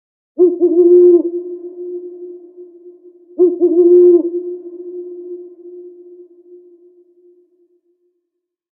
Owl Hooting At Night Sound Effect
The eerie call of an owl hooting at night creates a chilling atmosphere. This spooky bird sound effect captures the haunting noise of a predator owl.
Owl-hooting-at-night-sound-effect.mp3